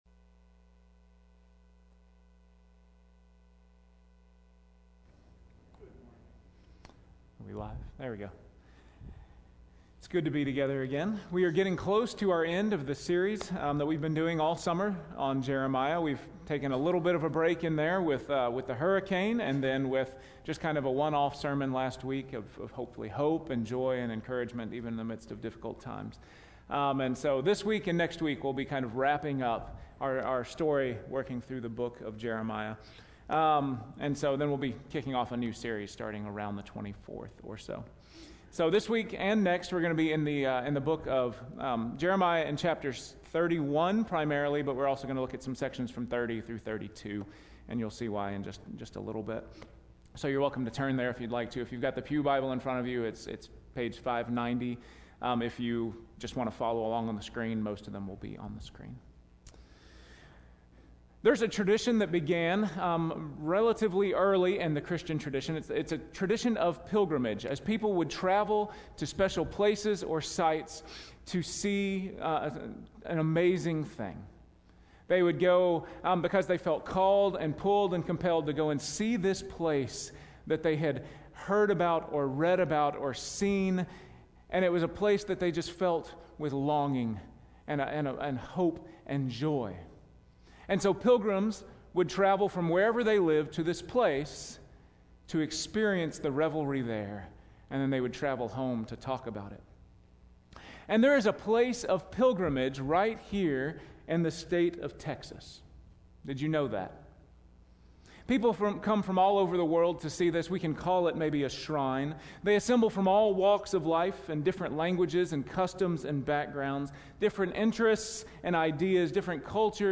Weekly Sermon Audio “Mourning into Dancing”